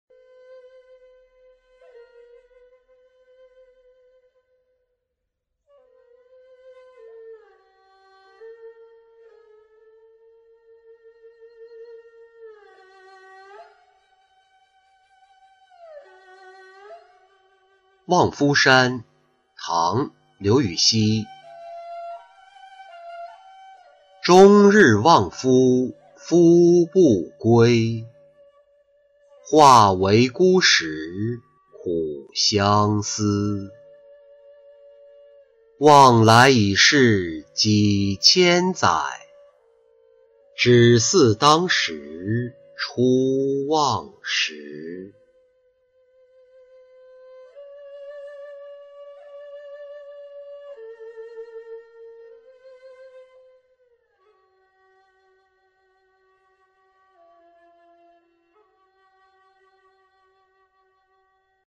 望夫山-音频朗读